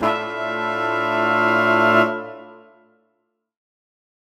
UC_HornSwell_Bsus4min6.wav